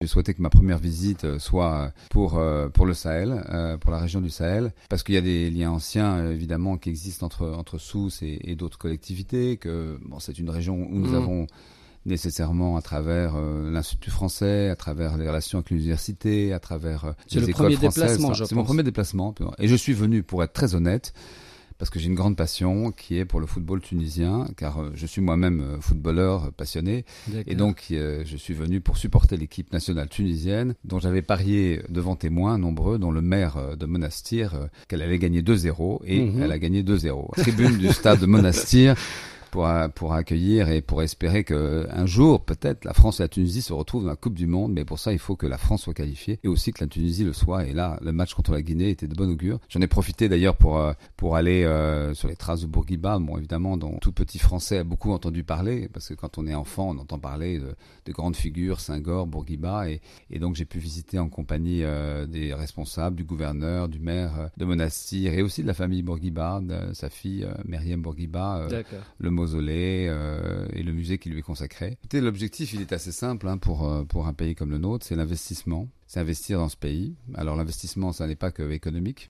في تصريح حصري : سفير فرنسا بتونس ضيف برنامج بوليتيكا